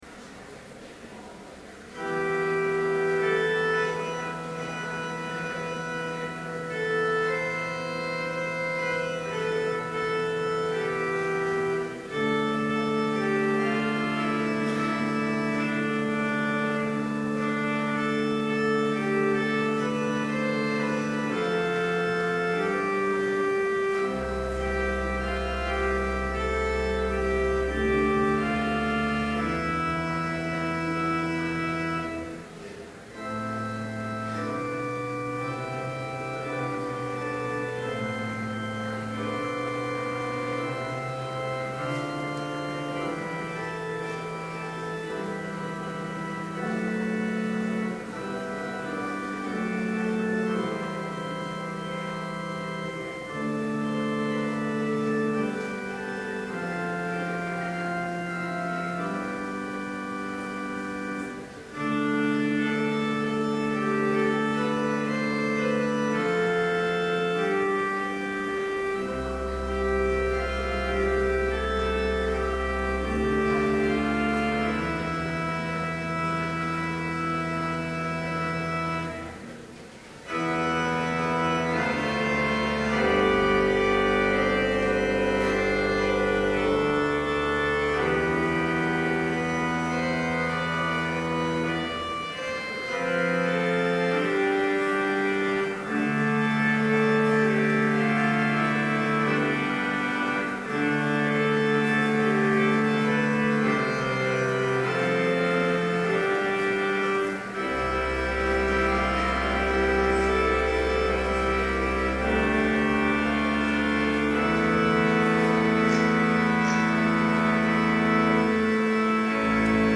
Complete service audio for Chapel - February 28, 2012